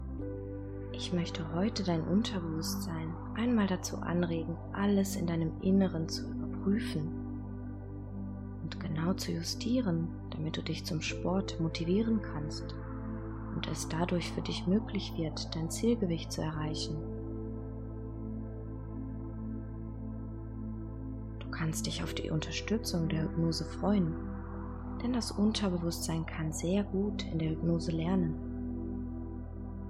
In Kombination mit sanfter Entspannungsmusik wird es Ihnen sehr leicht fallen, in einen herrlich tiefen Entspannungszustand zu sinken, um dann die Suggestionen für ein rauchfreies Leben effektiv in Ihr Unterbewusstsein zu integrieren.
Entspannende Hintergrundmusik sorgt dafür, dass Sie sich leicht fallen lassen können, um alle Suggestionen wirksam im Unterbewusstsein aufnehmen zu können.
Hörprobe: G2002 – Hypnosesitzung “Mehr Bewegung und Sport in den Alltag integrieren”